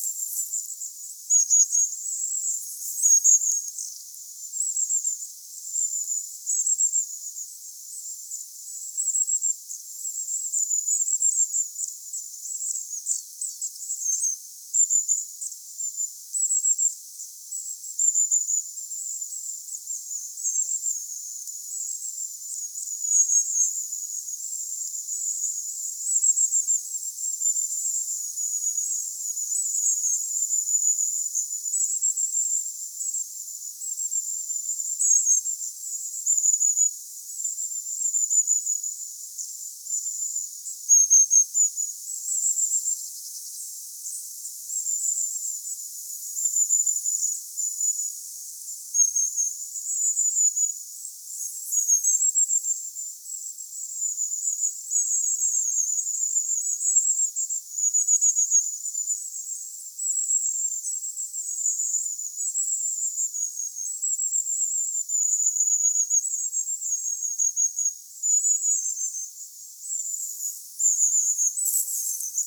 sen ison pyrstötiaisparven ääntelyä
sen_ison_pyrstotiaisparven_aantelya_saaressa.mp3